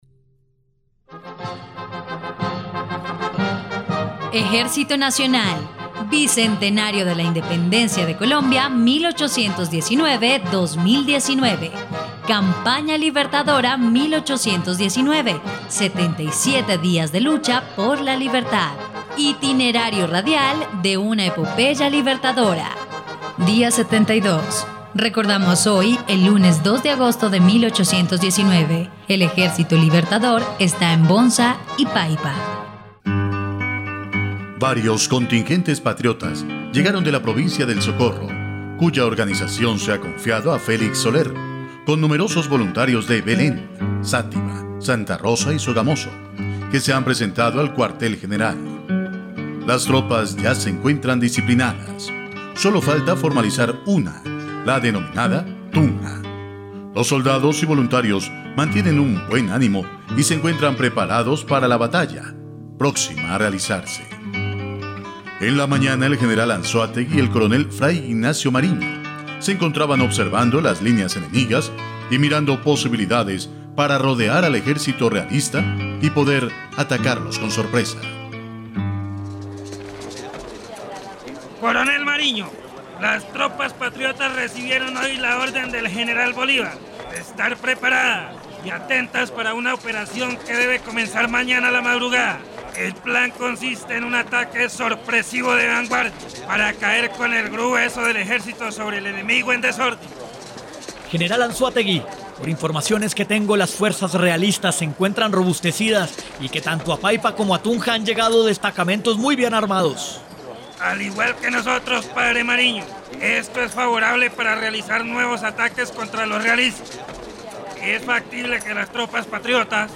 dia_72_radionovela_campana_libertadora.mp3